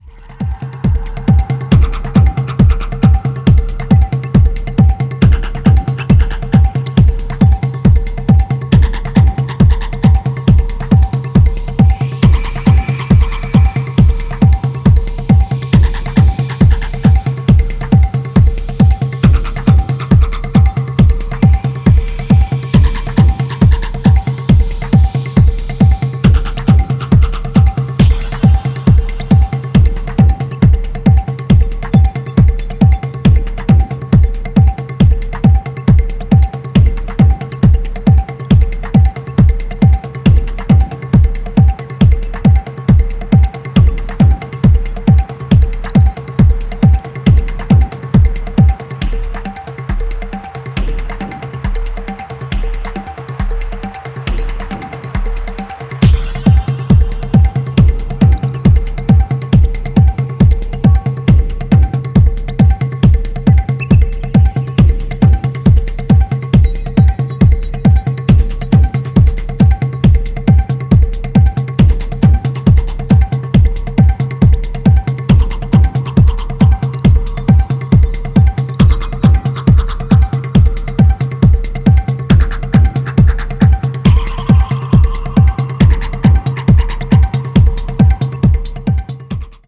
アンビエントなテイストだったアルバムとは打って変わって躍動感漲るダンストラックが揃った4トラックス。